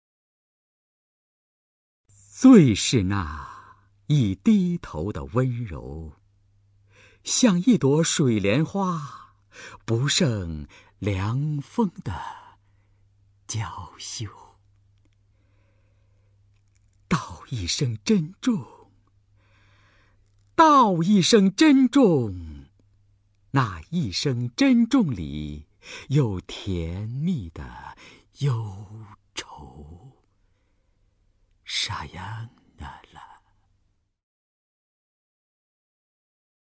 首页 视听 名家朗诵欣赏 刘纪宏
刘纪宏朗诵：《沙扬娜拉》(徐志摩)